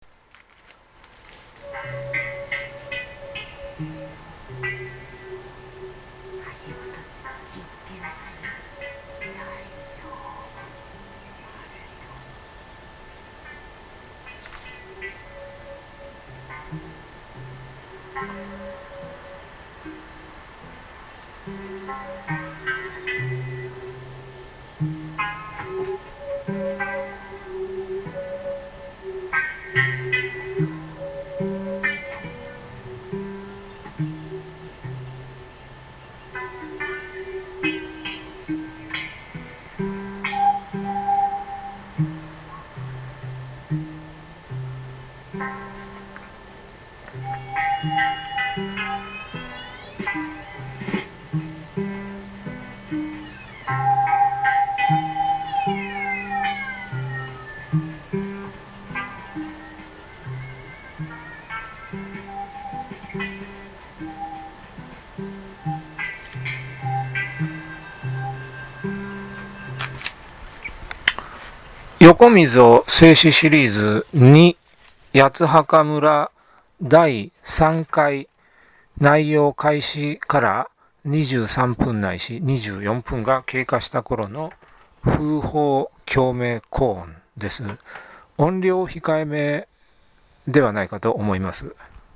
音量控えめではないか。